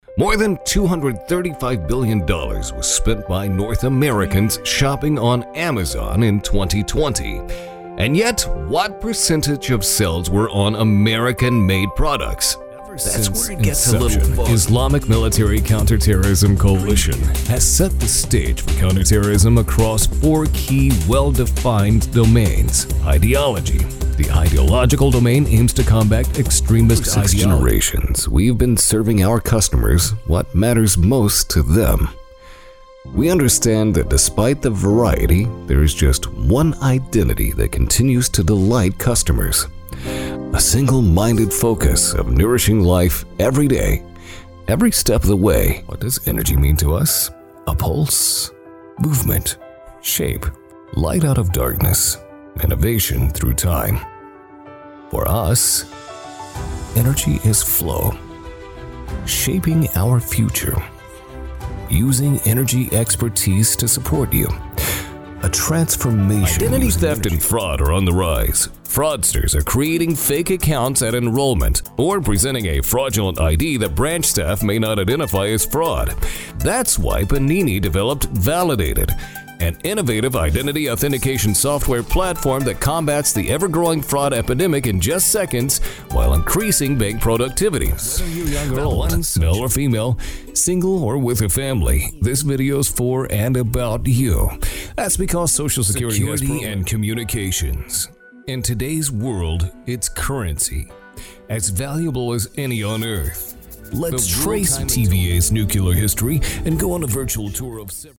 Since 2001, I’ve worked nationally and internationally as a voice over talent, delivering broadcast-ready reads for TV, radio, automotive, and documentary projects from a professional studio.
Corporate
Middle Aged